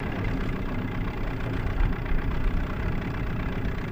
50 hp Motorboat Idles Steady